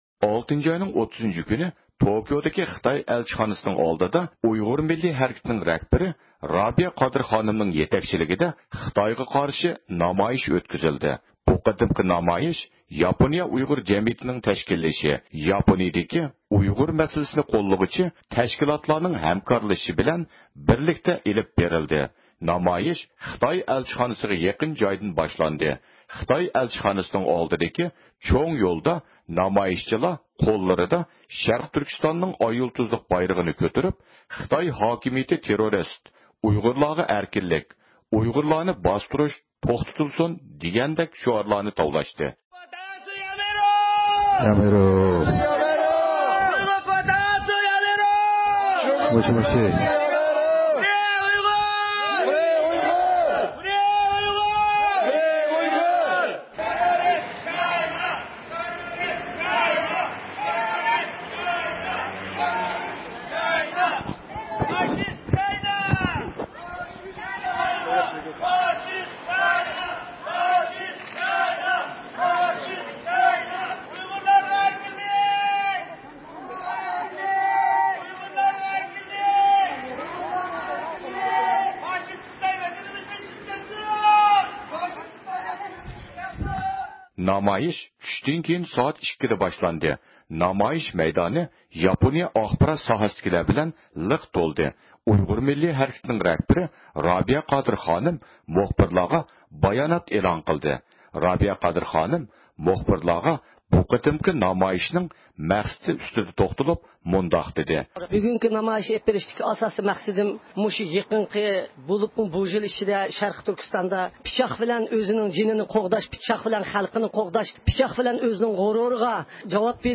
ئۇيغۇر مىللىي ھەرىكىتىنىڭ رەھبىرى رابىيە قادىر خانىم مۇخبىرلارغا بايانات ئېلان قىلدى.